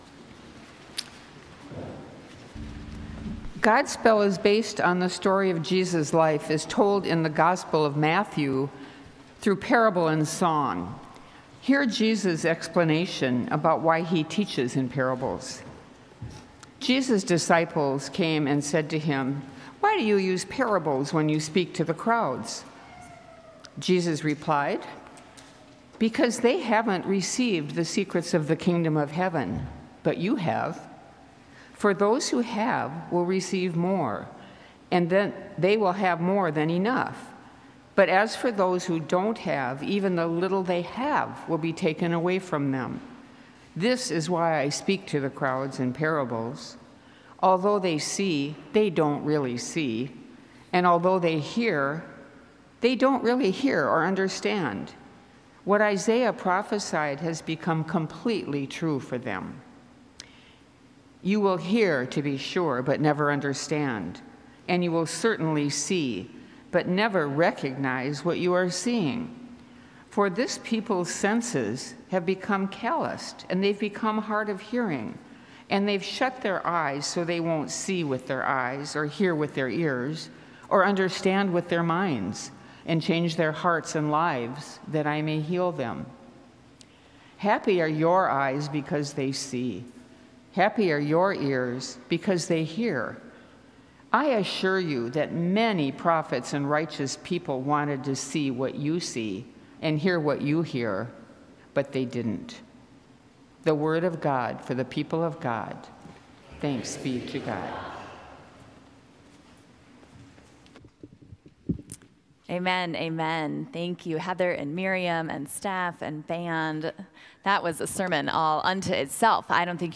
Join us for a special service featuring the music of Godspell—the beloved musical by Stephen Schwartz, the Grammy and Academy Award-winning composer of Wicked and Pippin. Lift your hearts in worship singing stirring selections from the Tony-nominated score, including “Day By Day,” “Light of the World,” and “Save the People.”